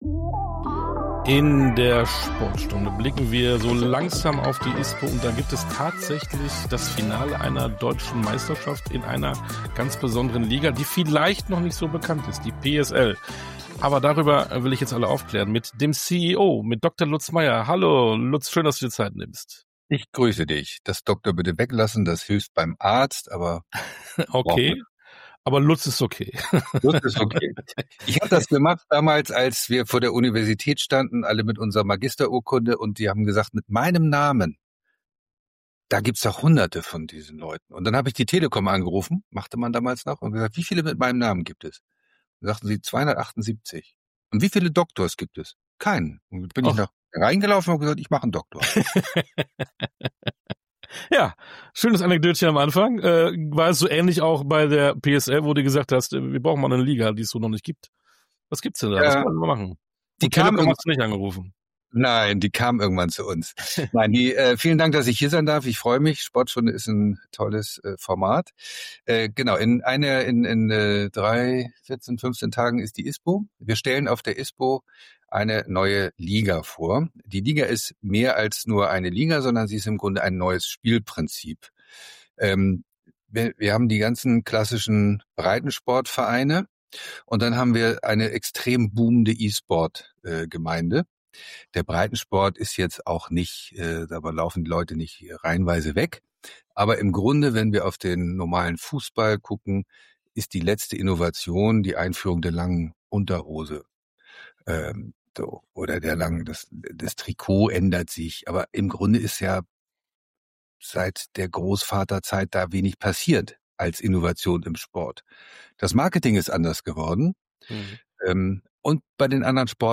~ Sportstunde - Interviews in voller Länge Podcast